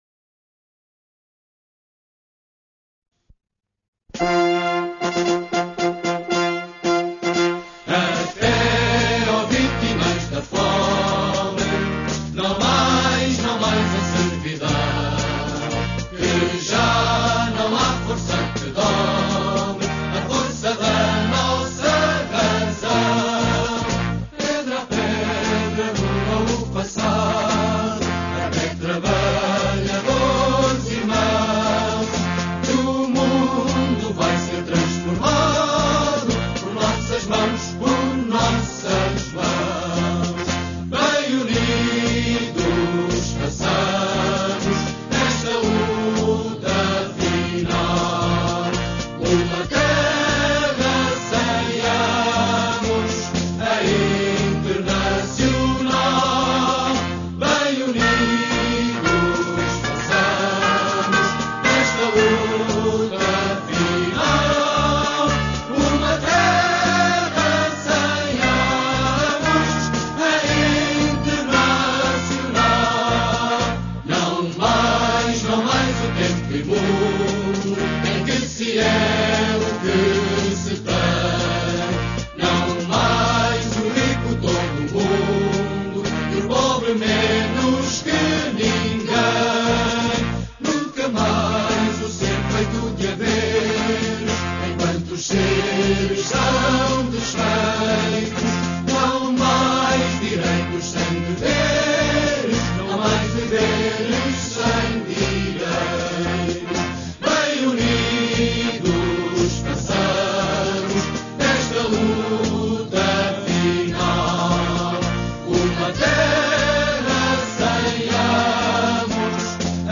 Португальская версия гимна в варианте партии социалистов